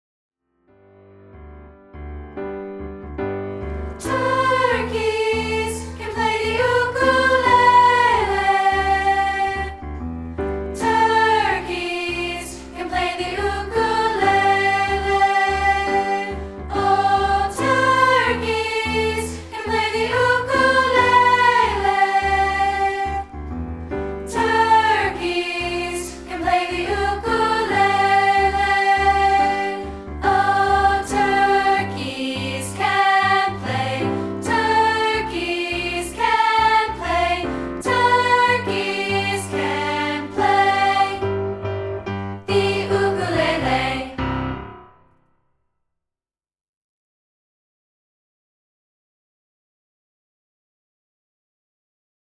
We're offering a rehearsal track of part 2, isolated